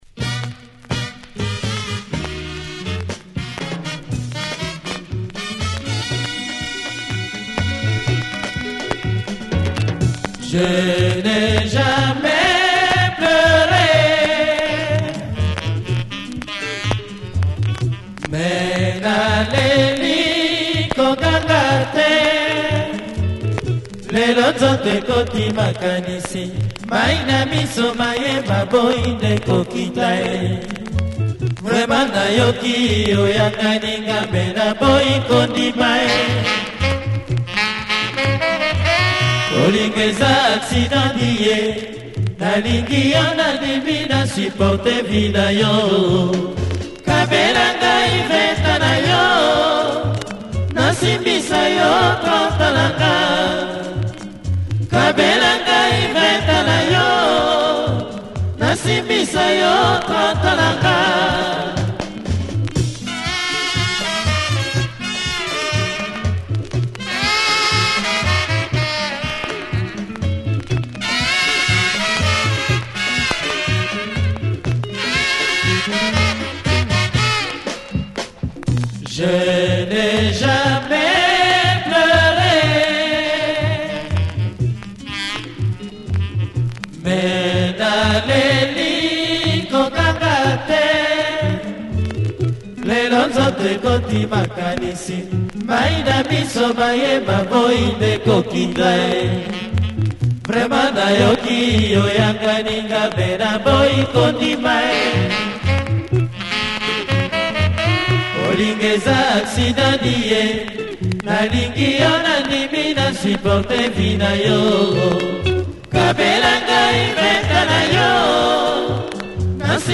Please check the audio from the actual 45 for sale.